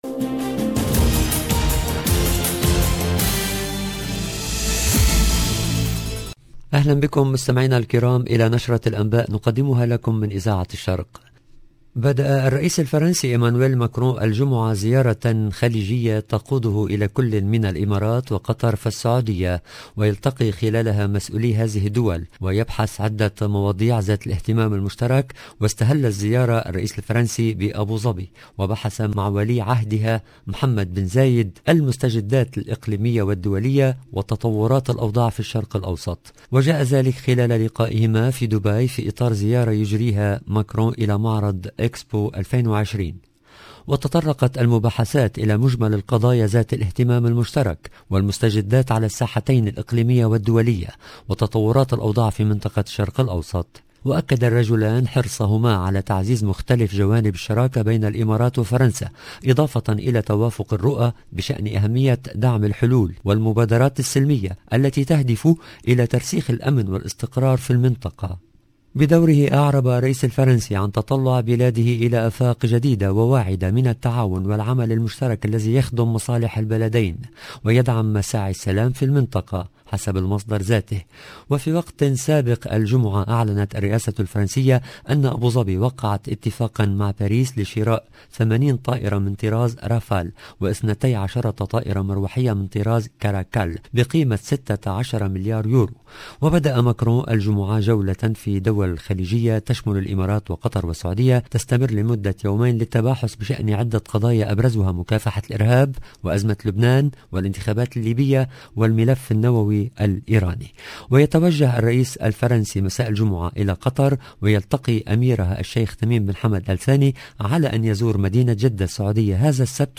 LE JOURNAL DU SOIR EN LANGUE ARABE DU 3/12/21